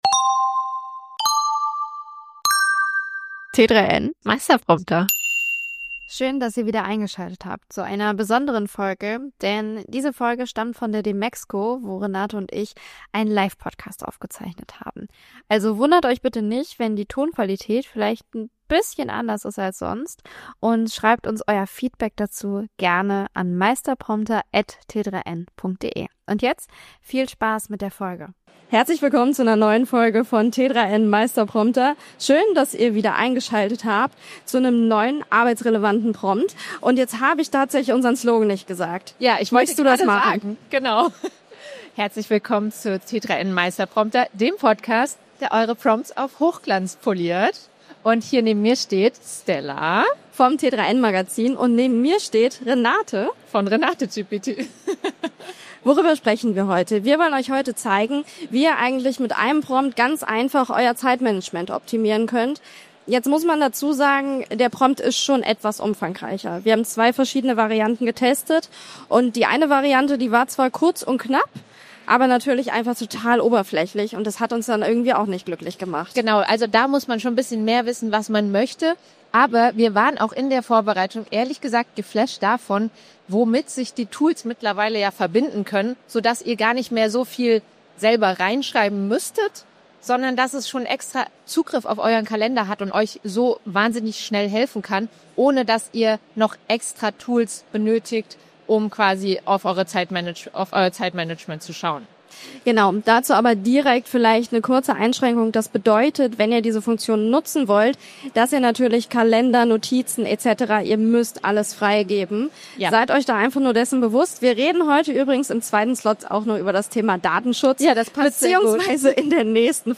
Hinweis: Diese Folge stammt aus einer Live-Aufzeichnung bei der Dmexco 2025.